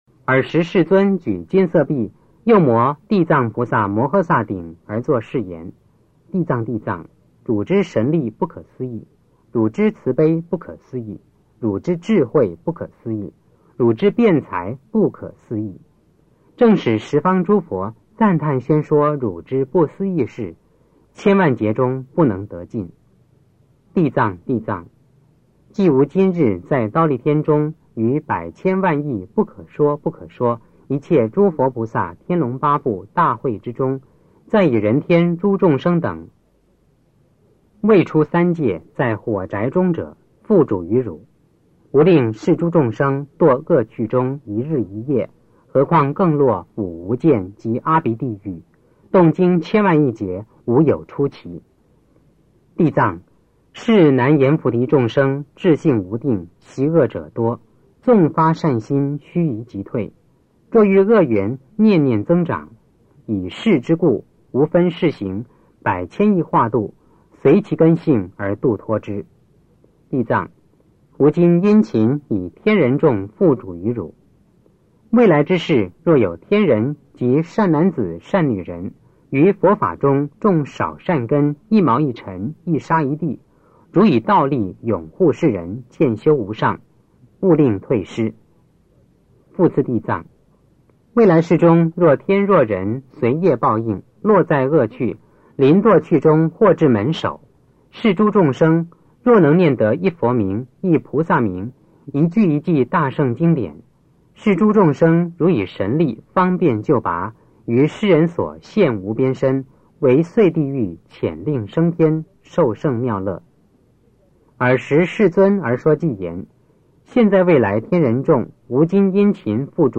地藏经(男声念诵）9 - 诵经 - 云佛论坛
地藏经(男声念诵）9